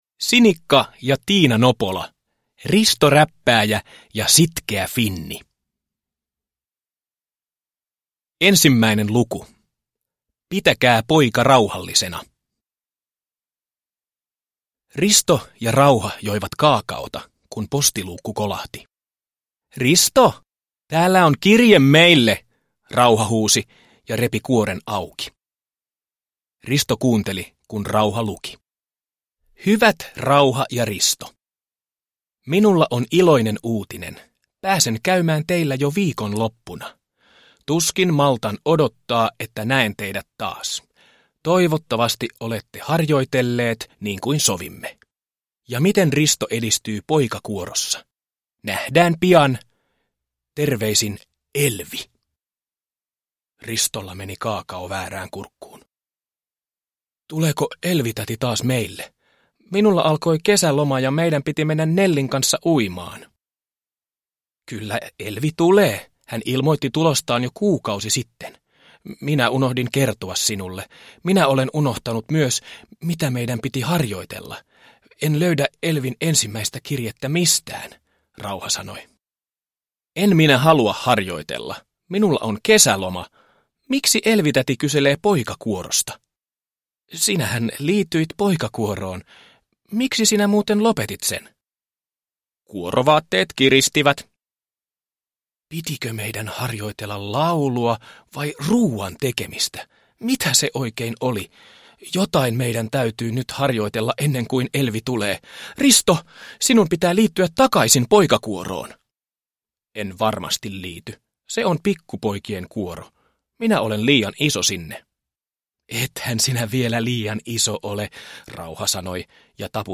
Risto Räppääjä ja sitkeä finni – Ljudbok
• Ljudbok